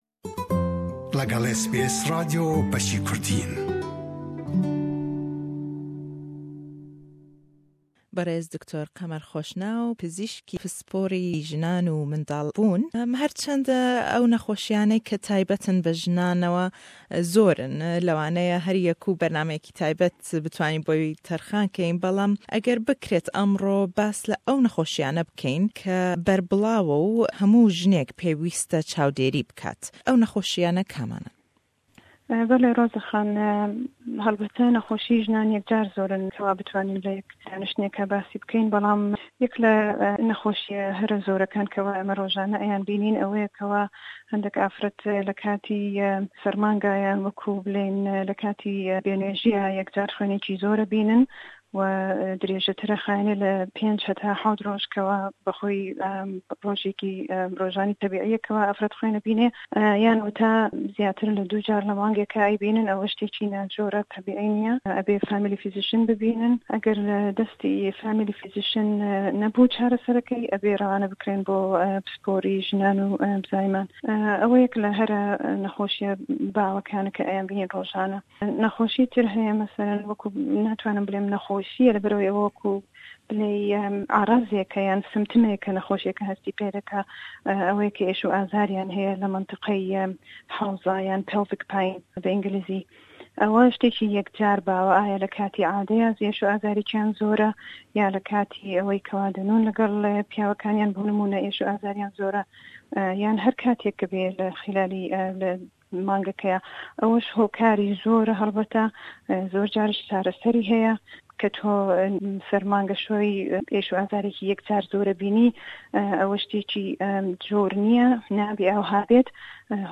Le em hevpeyvîn e da êman pirsiyarî nexoşêkanî jinan be giştî lê dekeyn, û be taybetî bas le taqîkirdinewe yan testî 'Pap-smear' dekeyn û gringî ew test e.